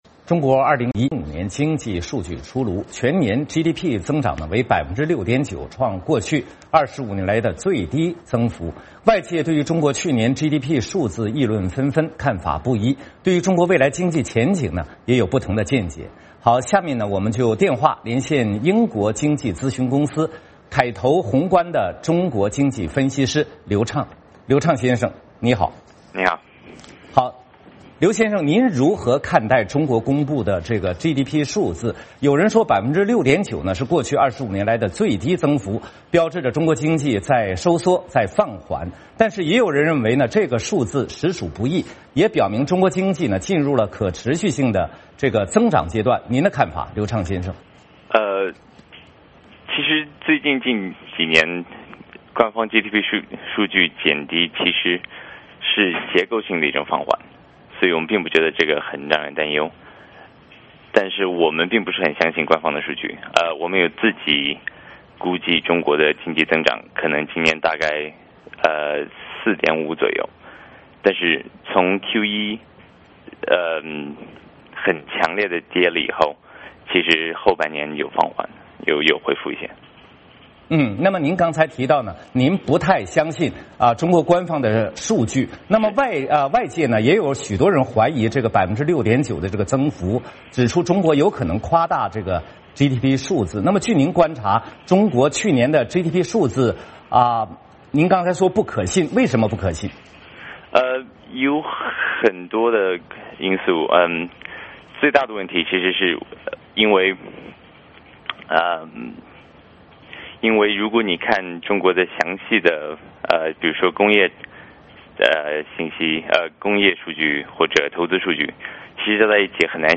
VOA连线：中国2015年经济数据出炉 专家谈未来经济前景